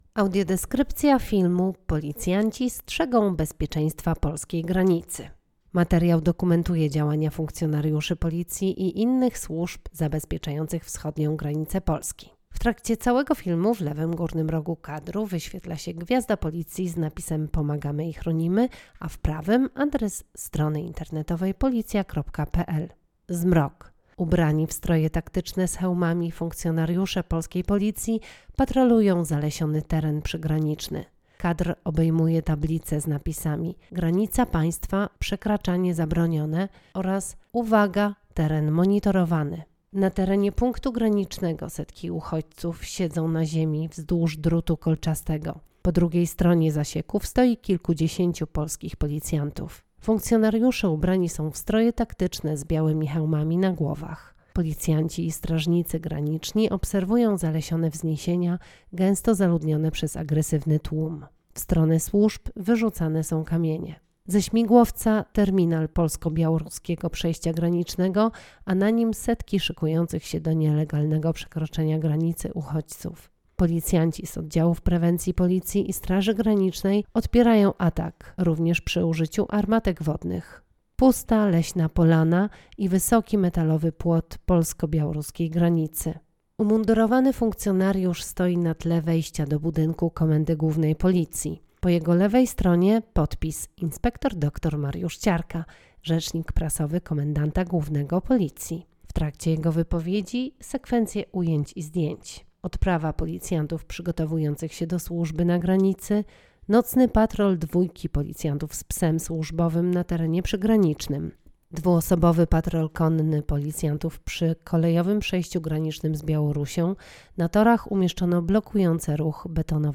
Nagranie audio Audiodeskrypcja do filmu: Policjanci strzegą bezpieczeństwa polskiej granicy